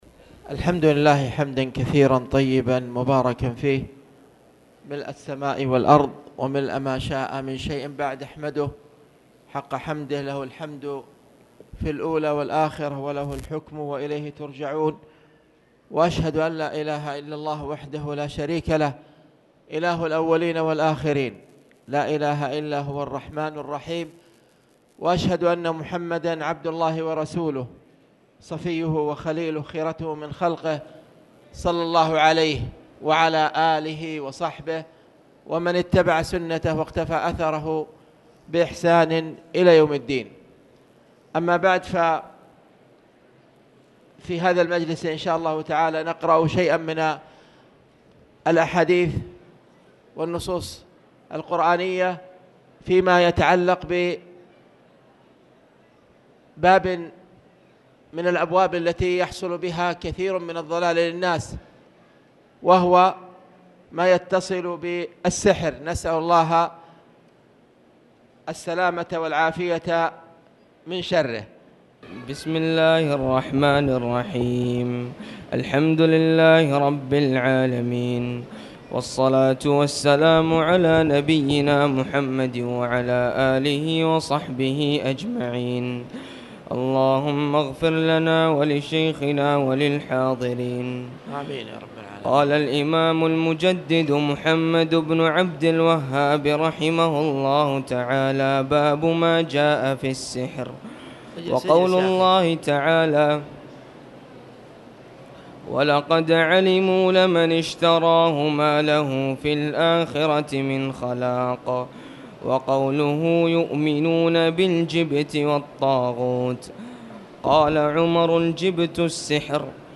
تاريخ النشر ٤ رجب ١٤٣٨ هـ المكان: المسجد الحرام الشيخ